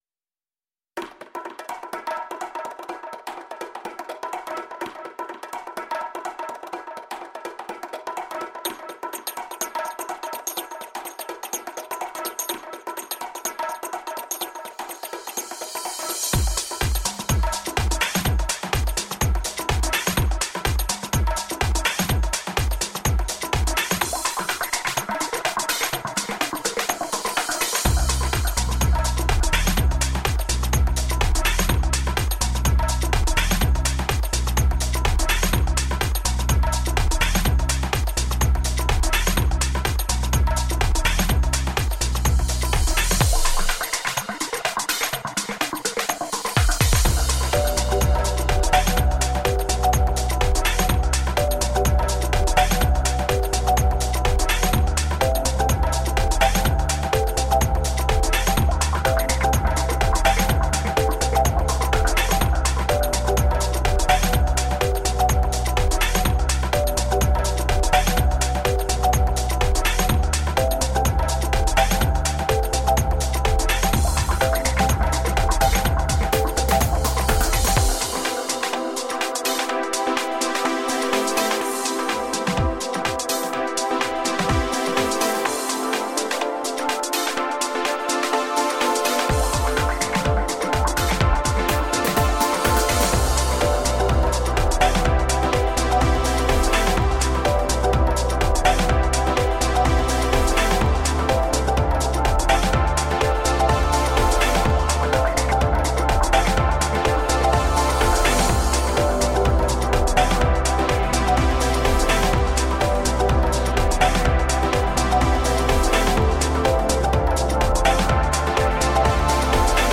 A summer cocktail of chill out, electronica and ambient.
Tagged as: Electronica, Other, Chillout